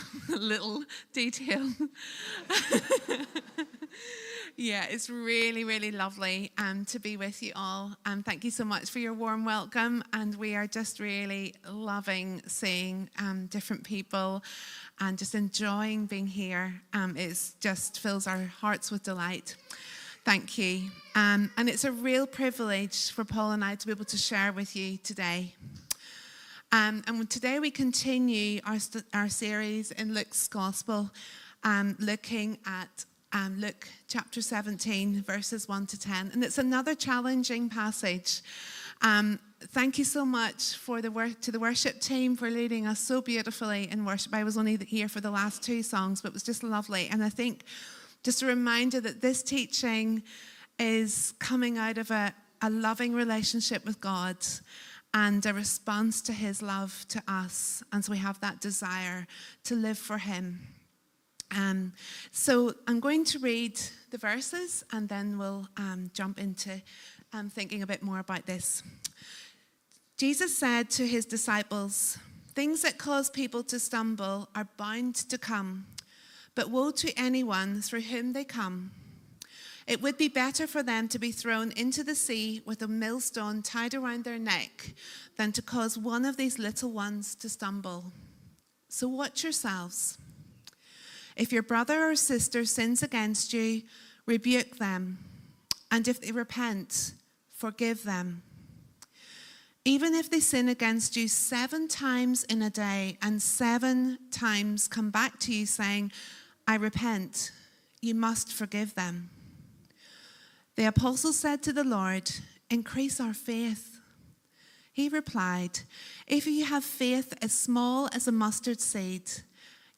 Walk this way | Sermon Series | Christchurch Baptist, Welwyn Garden City